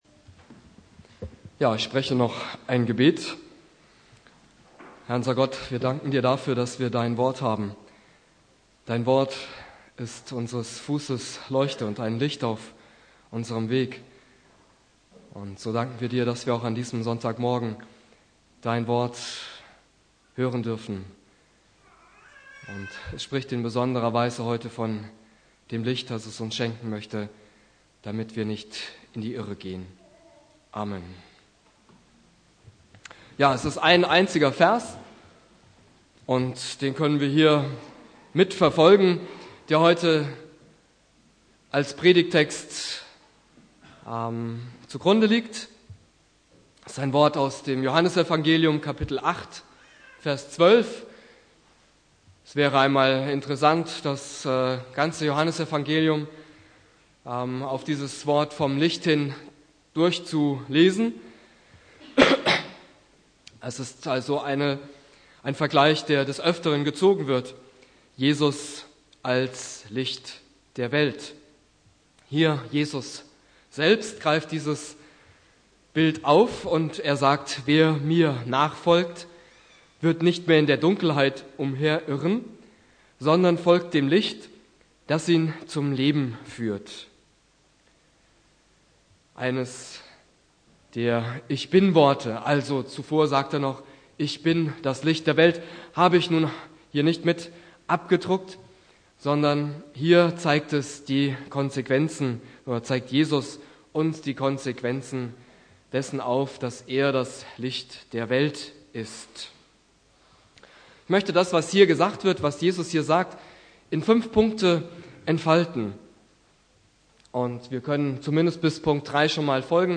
Predigt
2.Weihnachtstag Prediger